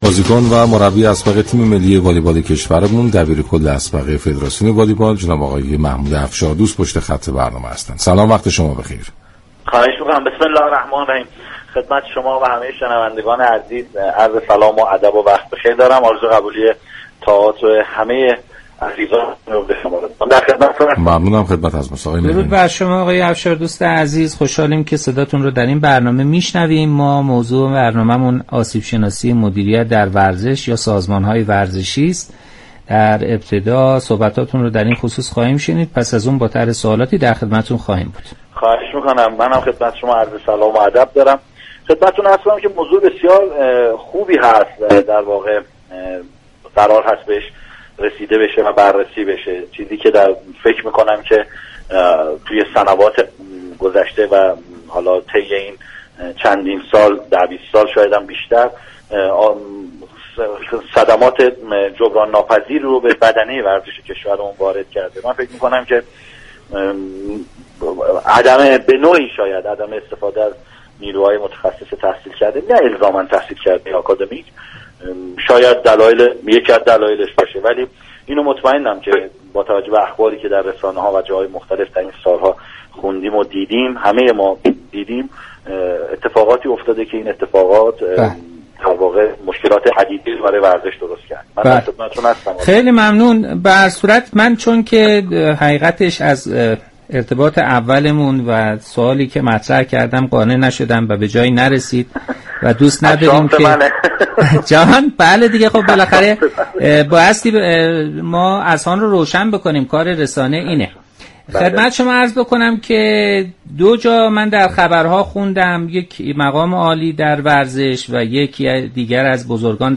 این برنامه با رویكرد بحث و بررسی درباره موضوعات روز ورزش كشور همه روزه به جز جمعه ها ساعت 18:30 به مدت 90 دقیقه از شبكه رادیویی ورزش تقدیم علاقمندان می شود.